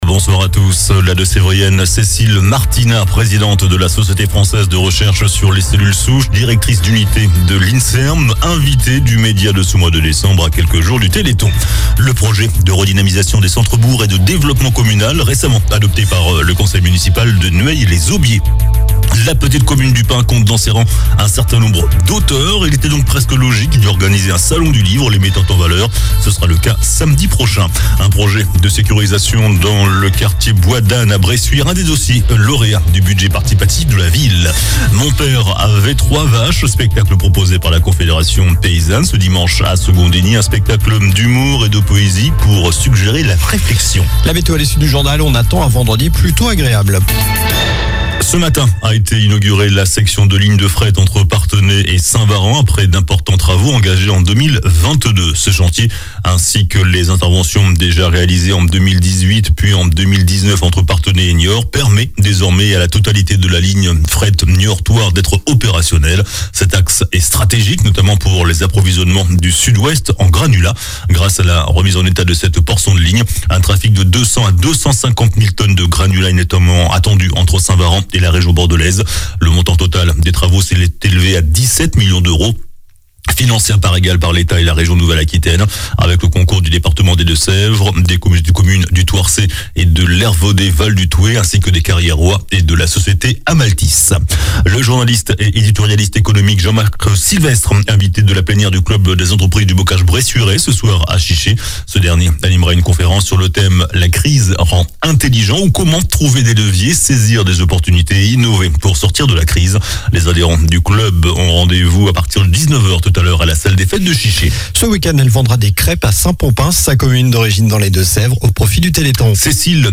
Journal du jeudi 07 décembre (soir)